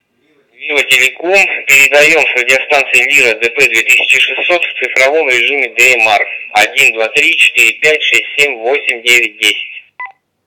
Пример модуляции (передачи) радиостанций серии DP2000 в цифровом режиме DMR:
lira-dp2000-tx-dmr.wav